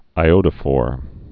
(ī-ōdə-fôr)